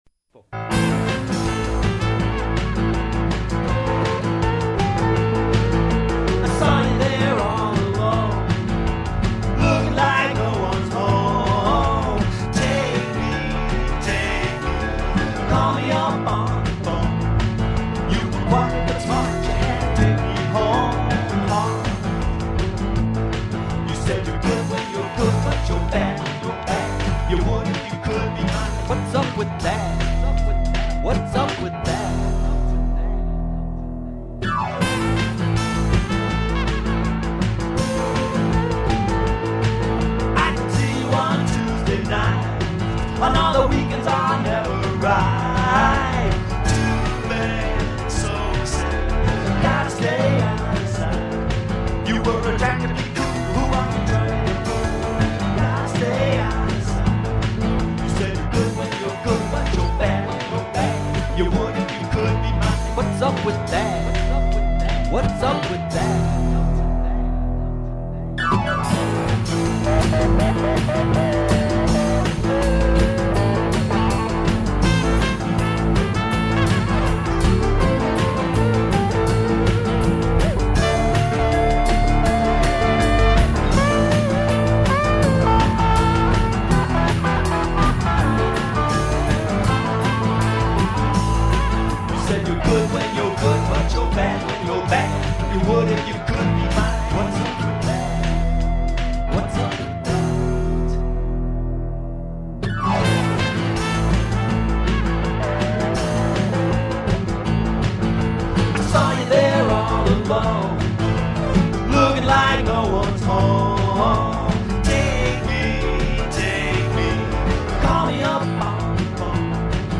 A Flirty Rockabilly Song
A Rock, Boogie, flirty/cheaty song.
Some of the MP3s are at Dial-up quality (24-32kbps)